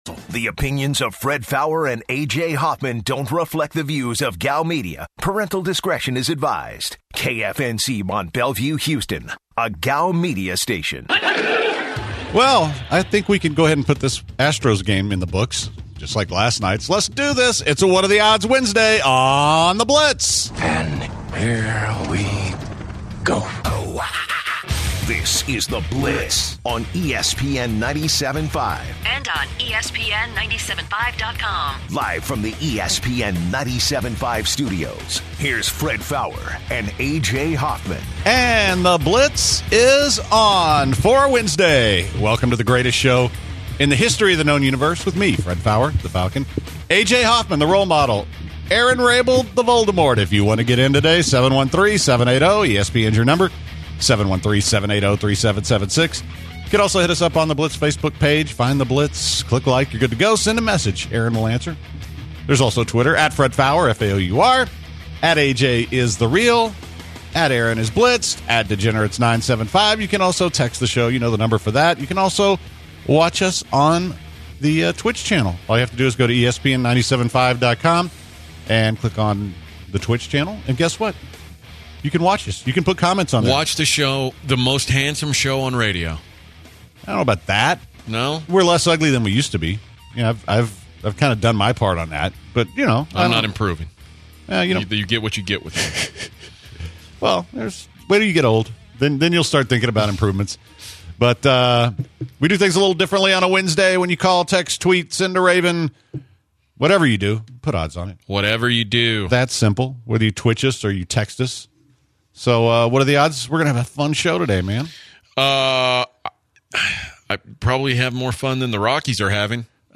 of The Bench calls into the show to break the news that the Texans have signed QB Jordan Ta’amu.